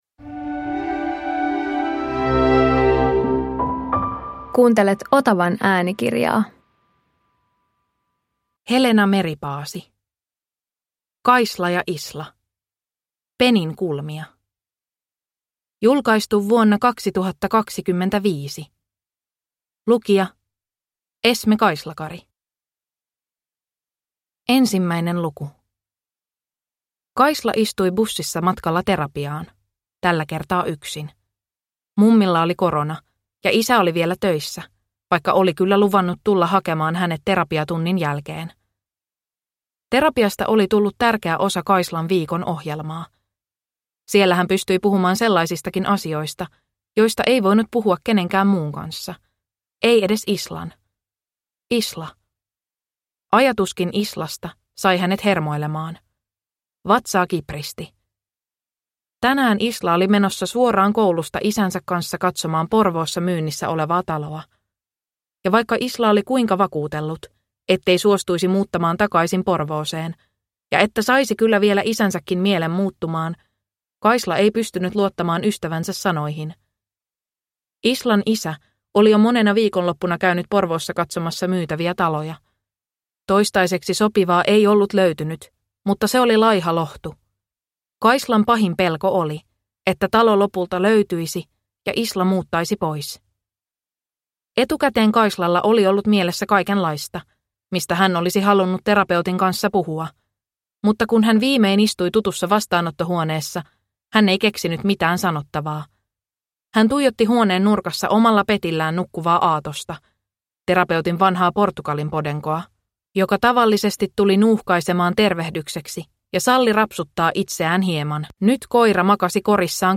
Kaisla ja Isla - Peninkulmia (ljudbok) av Helena Meripaasi